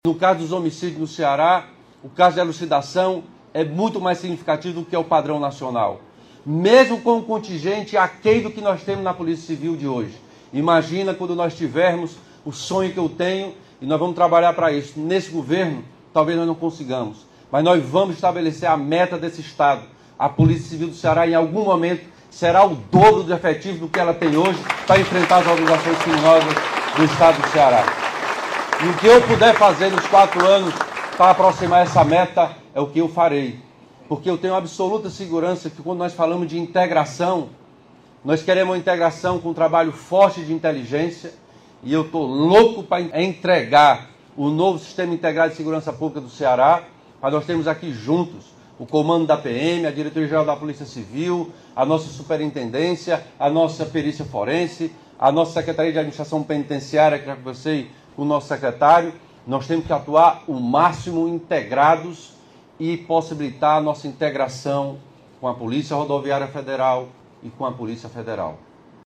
Com essas palavras, o governador Elmano de Freitas iniciou o seu discurso na solenidade de transmissão do cargo de delegado-geral da Polícia Civil do Ceará (PC-CE), na manhã desta terça-feira (31), na sede do Complexo de Delegacias Especializadas (Code), em Fortaleza.